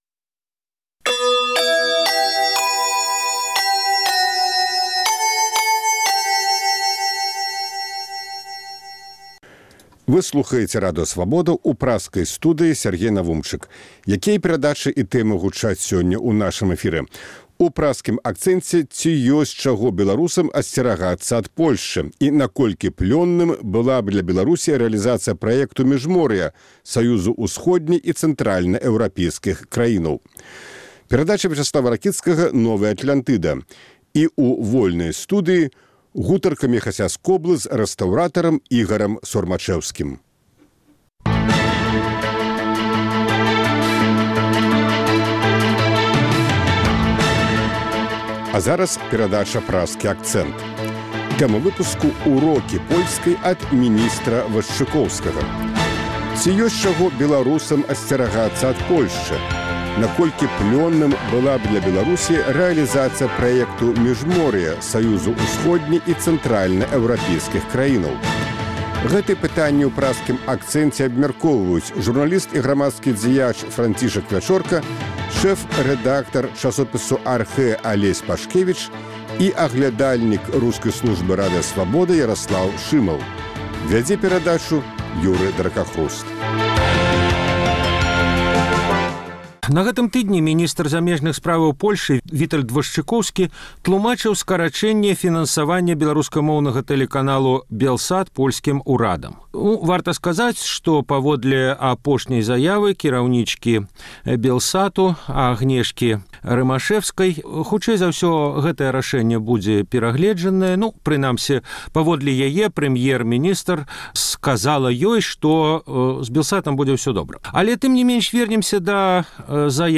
Ці ёсьць чаго беларусам асьцерагацца ад Польшчы? Наколькі плённым была б для Беларусі рэалізацыя праекту Міжморья – саюзу ўсходне- і цэнтральнаэўрапейскіх краінаў? Гэтыя пытаньні ў Праскім акцэнце абмяркоўваюць журналіст і грамадзкі дзяяч